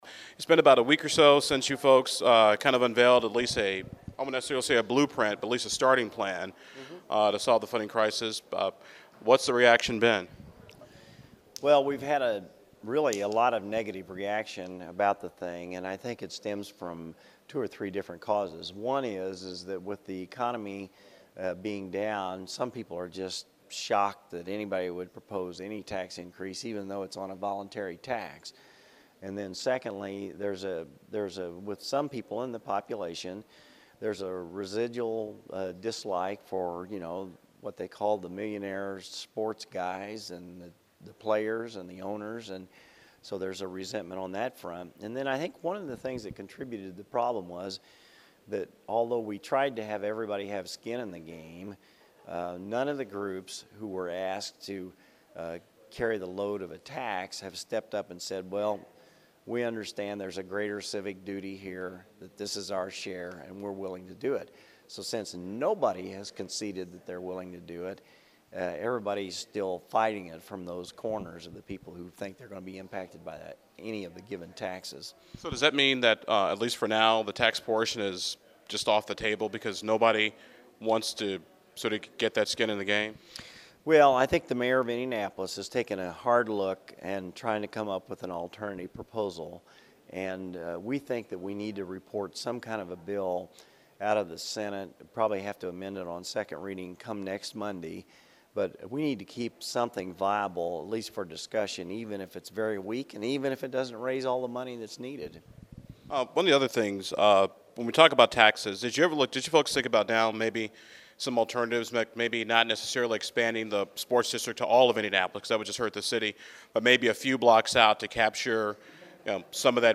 You can hear my entire conversation with Senator Kenley by clicking the link below.